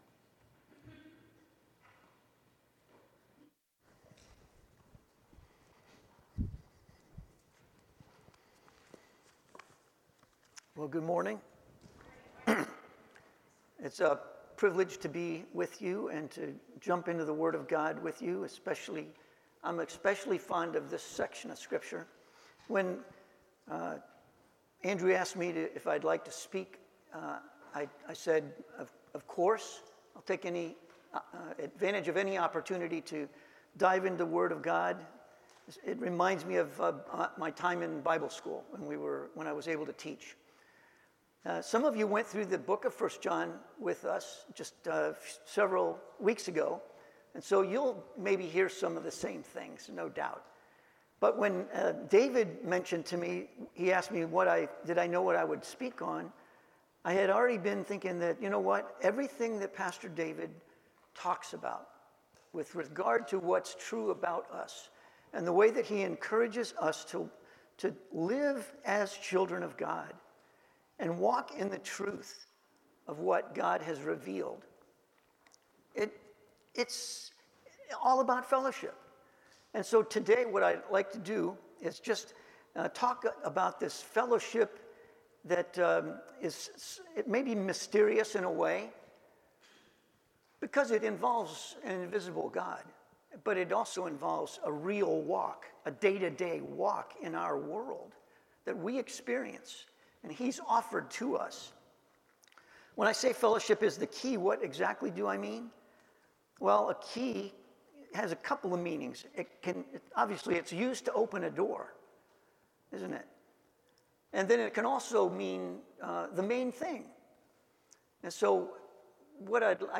Passage: 1 John 1:1-7 Sermon